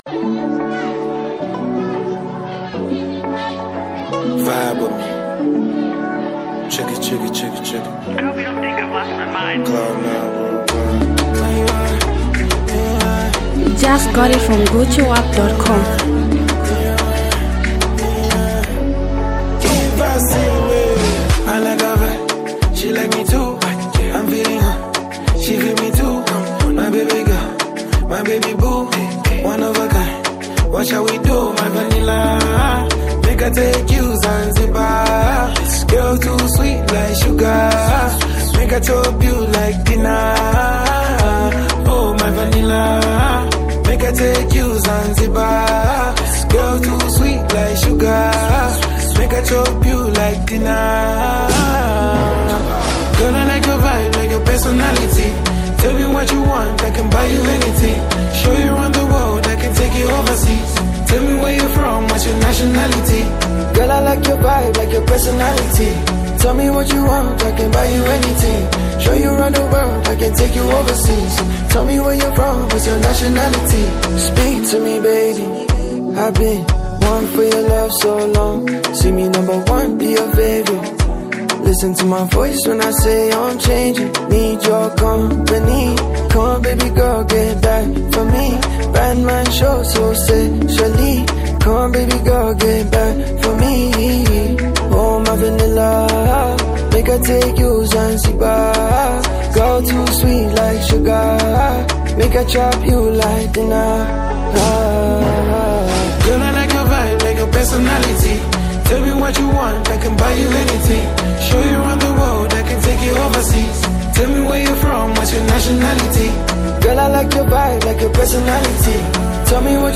melodic hit song